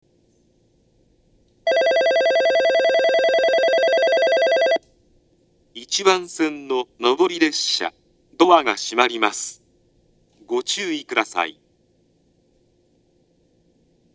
発車ベル（低音）
発車の際には低音のベルが流れます。